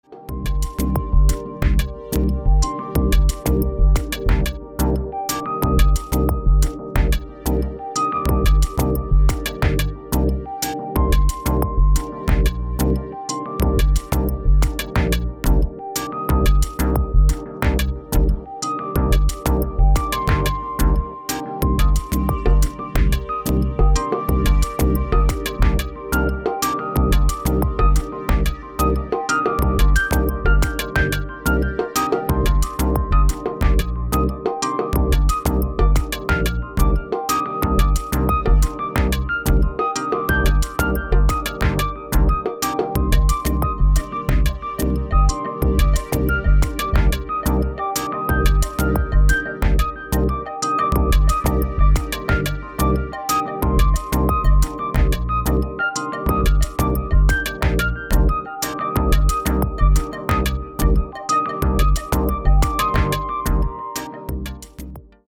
• Качество: 256, Stereo
Electronic
электронная музыка
спокойные
без слов
chillout
Downtempo
расслабляющие